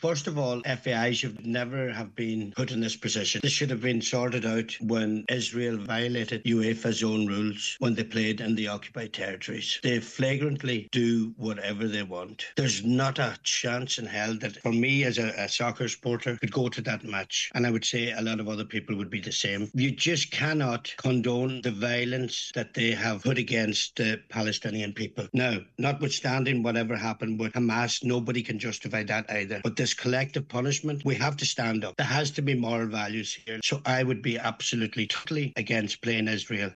Cllr Denis McGee says he would be totally against playing the game: